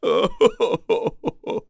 Chase-Vox_Sad.wav